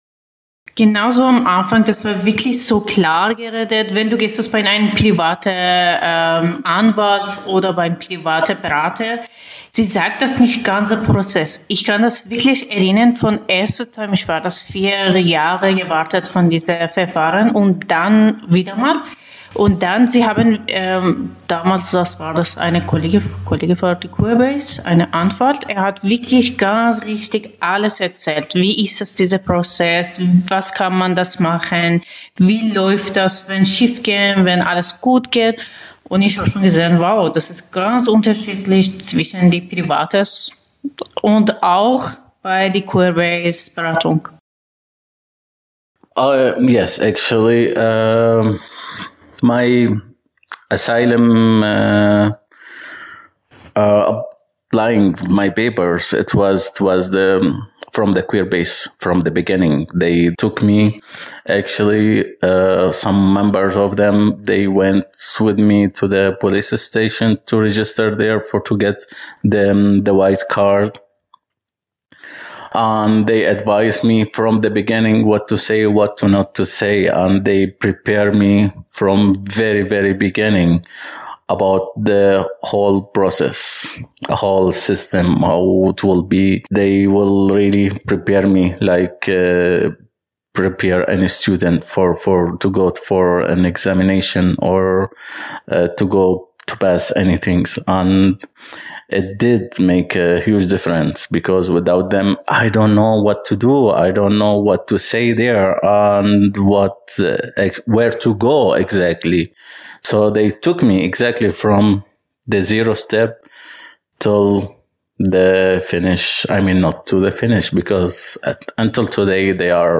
Oral History: Die Geschichte queerer Migration und Flucht ist geprägt von dem Streben nach Freiheit und Selbstbestimmung.